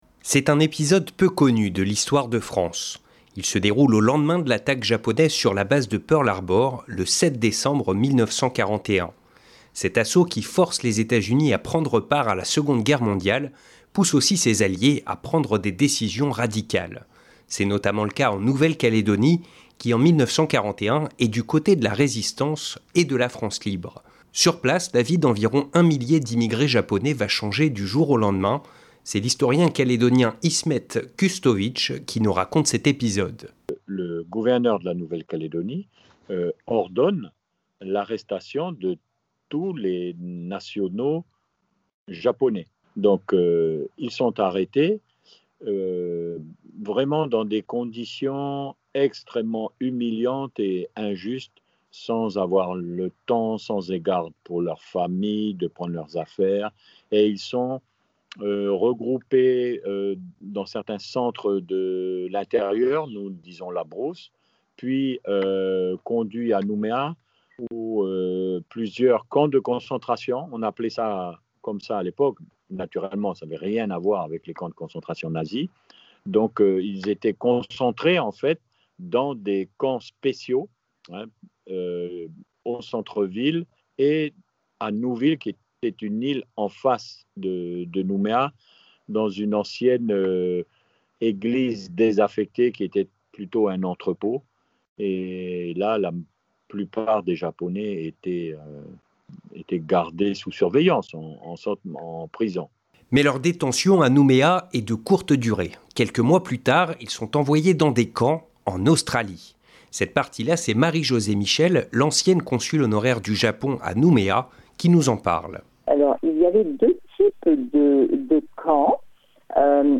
Au lendemain de l'attaque sur Pearl Harbour, le 7 décembre 1941, les autorités à Nouméa font arrêter tous les Japonais, avant de les envoyer dans des camps de prisonniers en Australie... Nous avons interrogé des chercheurs et des descendants de cette génération sacrifiée, car Japonais.